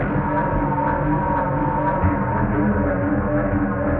精神病循环
描述：像古怪的沃克斯
Tag: 120 bpm Psychedelic Loops Synth Loops 689.84 KB wav Key : D FL Studio